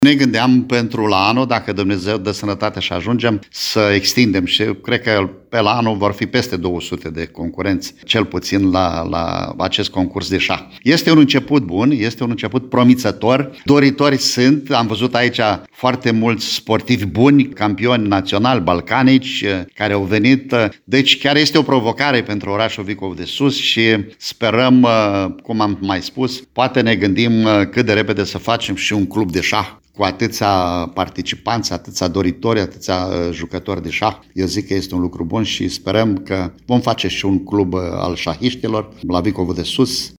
Primarul VASILE ILIUȚ a declarat postului nostru că șahul se bucură de o tot mai mare popularitate în nordul județului Suceava.